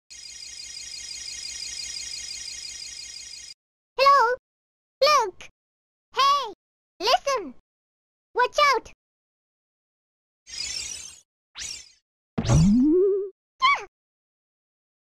navi hey listen all sounds.mp3